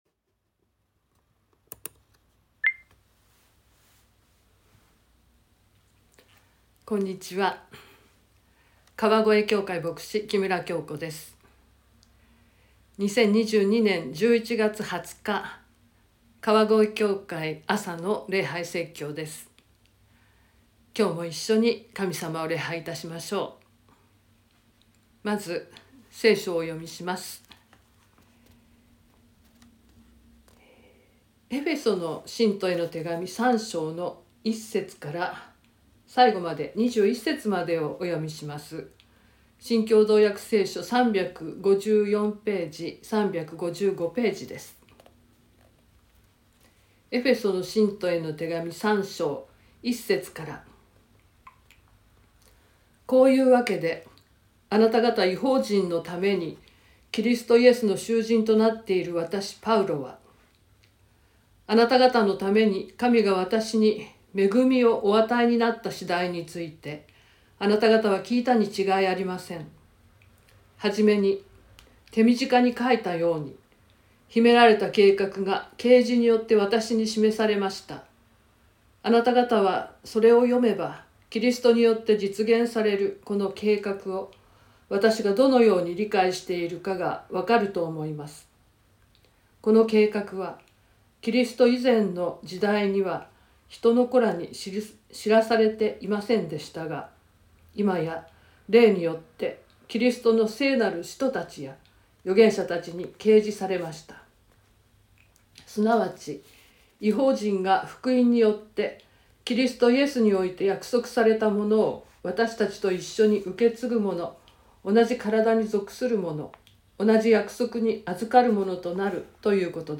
2022年11月20日朝の礼拝「福音に仕える力」川越教会
説教アーカイブ。